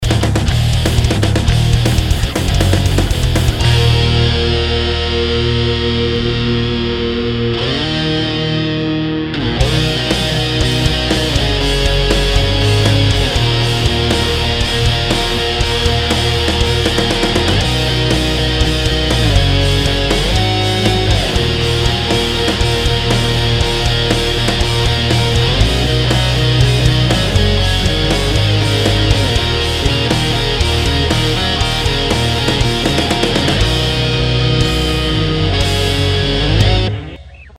(guitar cover)